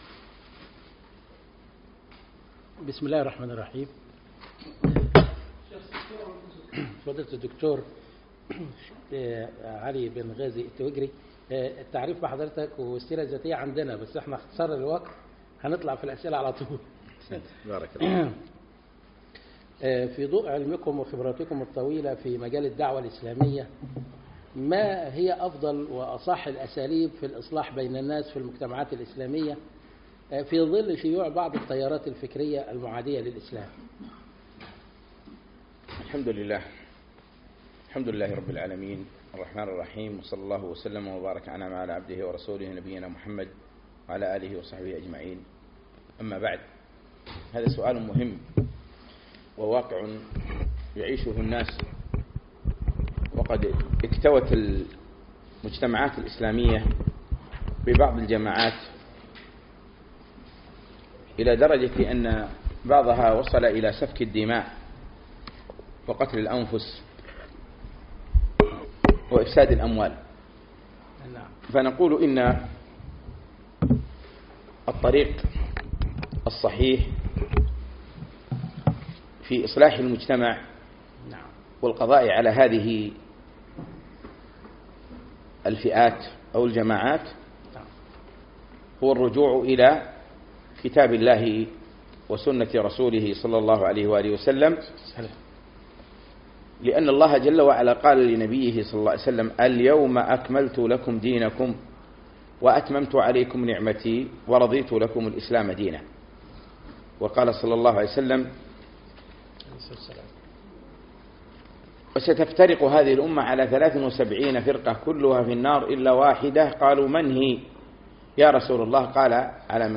الأثنين 3 4 2017 بعد صلاة العشاء بمكتبة مسجد صالح الكندري
لقاء صحفي مع مجلة الكويت - وزارة الإعلام